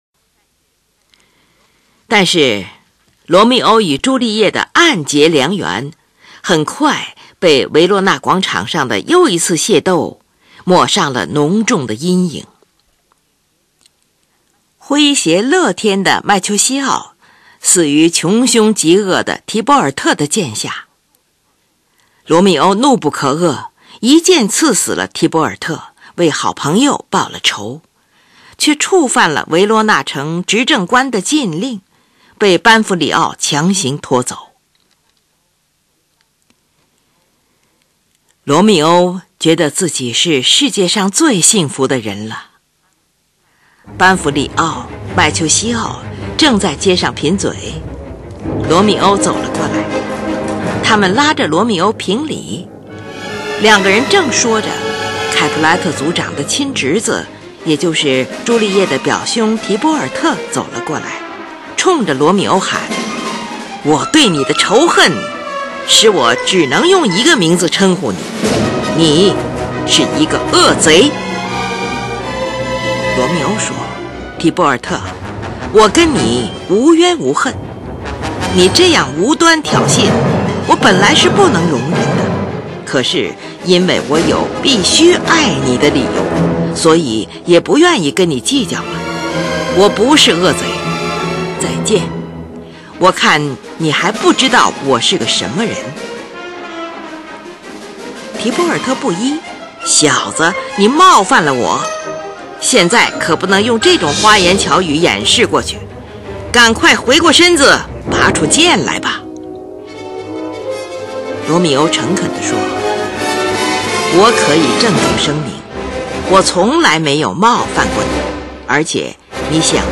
乐曲开始时，定音鼓和钢琴的和弦节奏使音乐充满了紧迫感。